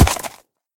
horse_land.ogg